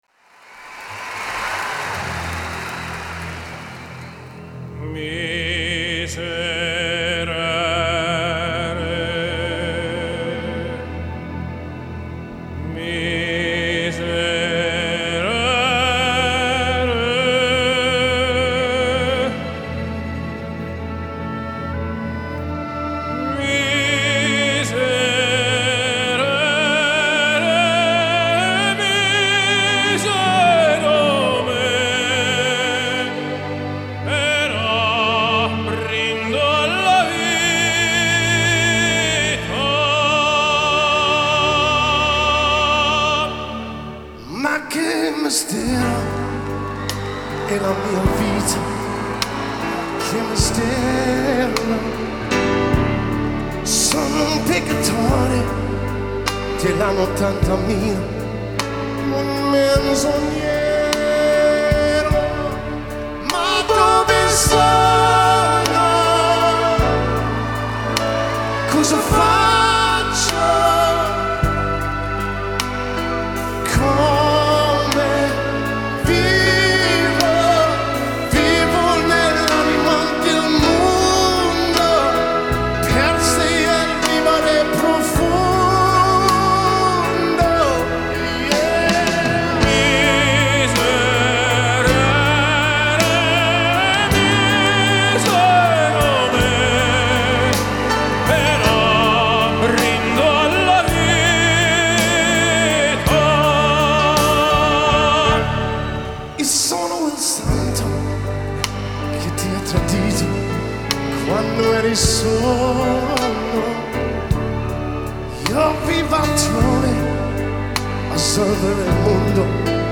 Стили: Pop/Classical/Vocal/Crossover